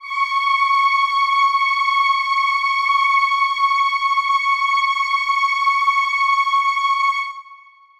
Choir Piano (Wav)
C#6.wav